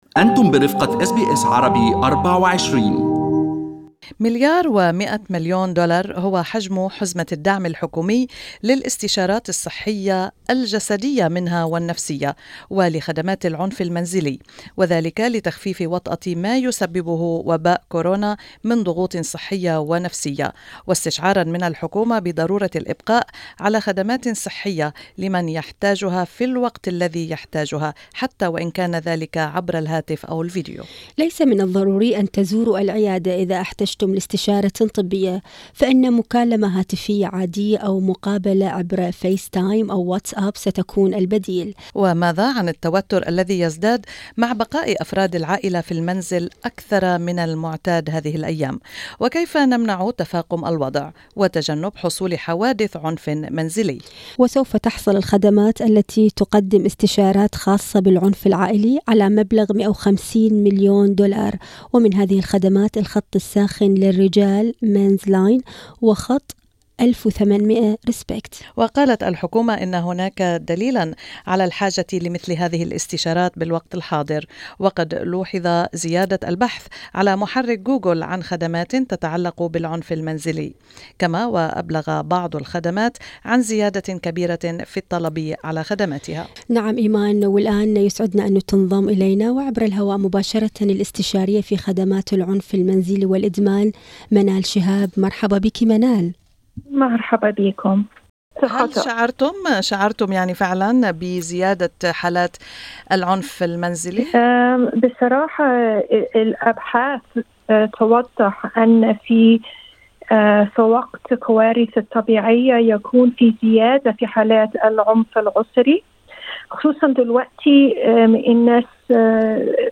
استمعوا الى اللقاء كاملا تحت الشريط الصوتي في أعلى الصحفة.